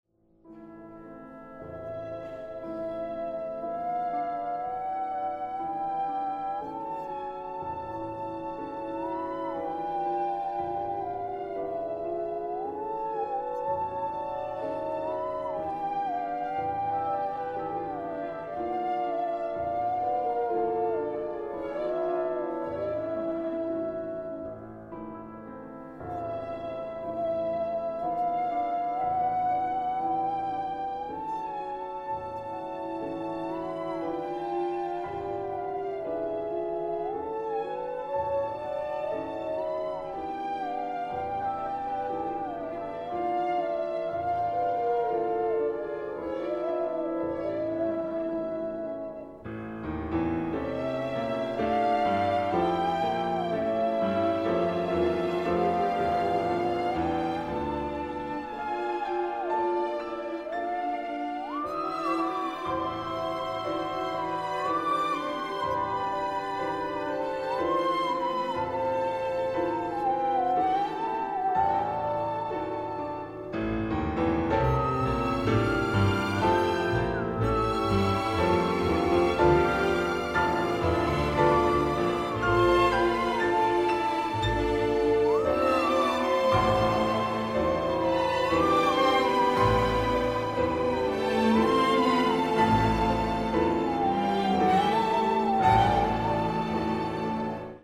1. with Musical saw